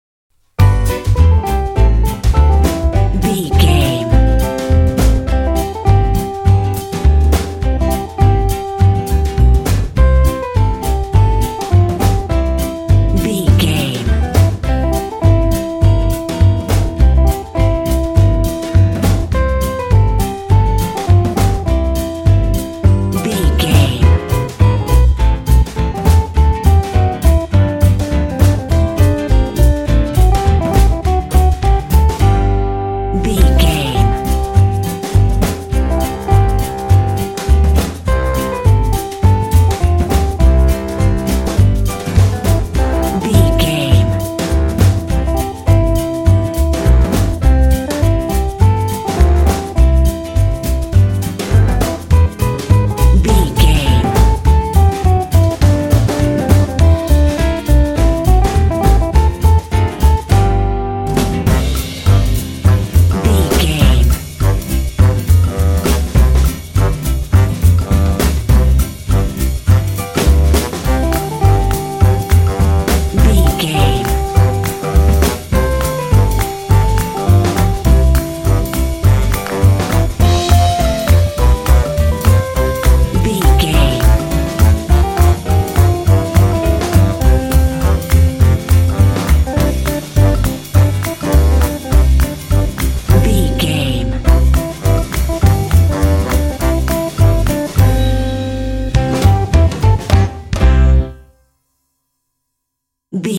Ionian/Major
playful
uplifting
calm
cheerful/happy
drums
bass guitar
acoustic guitar
piano
saxophone